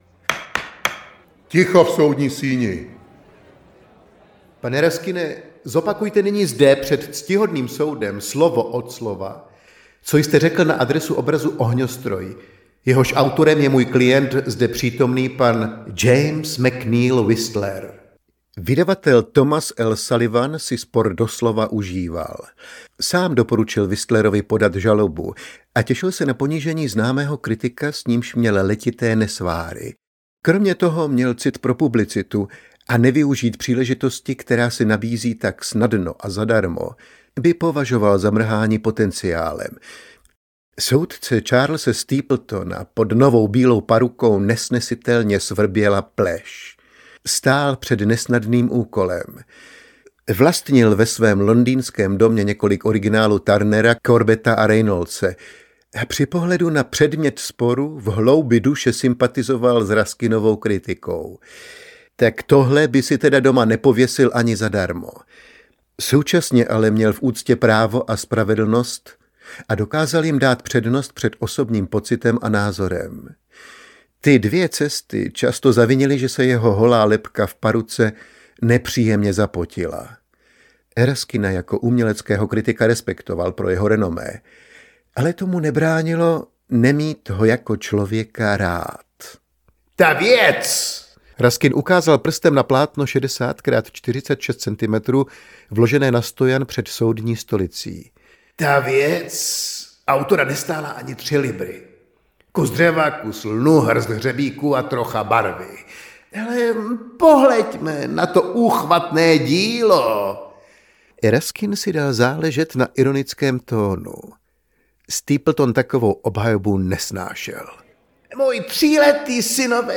Ztracené plátno audiokniha
Ukázka z knihy